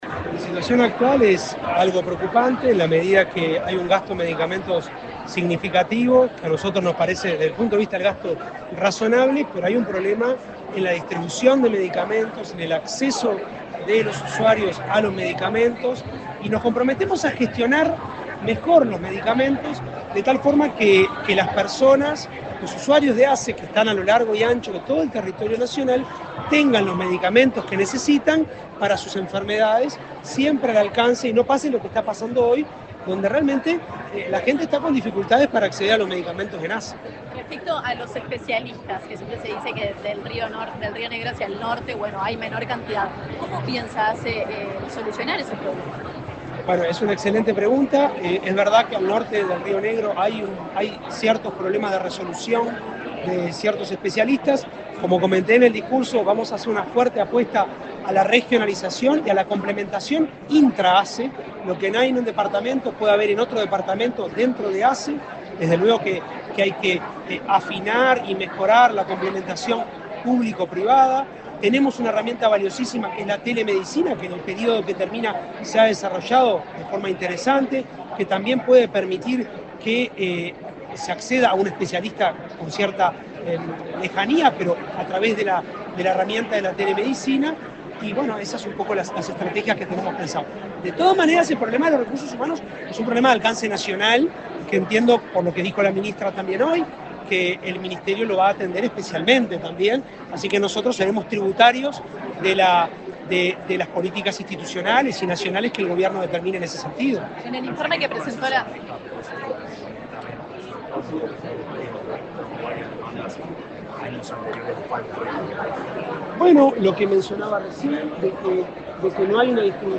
Declaraciones del presidente de ASSE, Álvaro Danza
Declaraciones del presidente de ASSE, Álvaro Danza 02/04/2025 Compartir Facebook X Copiar enlace WhatsApp LinkedIn El presidente de la Administración de los Servicios de Salud del Estado (ASSE), Álvaro Danza, dialogó con la prensa, luego del acto en el que asumió al frente del prestador público de salud.